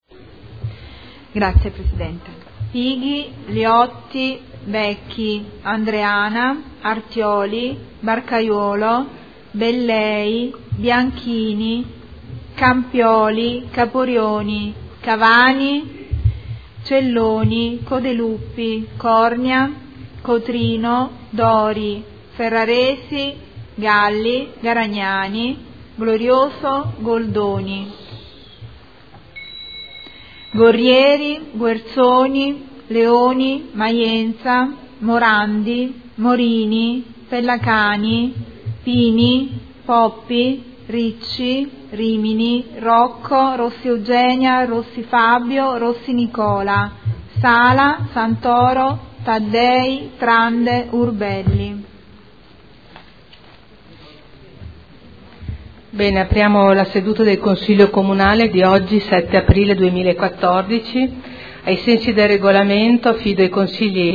Segretario Generale — Sito Audio Consiglio Comunale
Seduta del 7 aprile. Appello